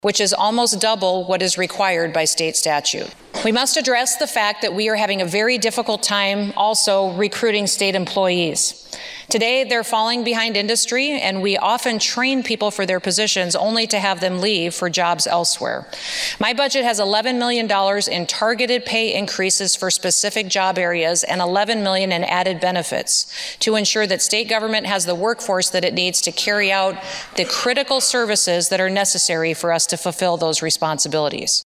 South Dakota Governor Kristi Noem delivered her 2022 Budget Address today (Dec. 6, 2022), outlining her spending plan for part of Fiscal Year 2023 and all of Fiscal Year 2024.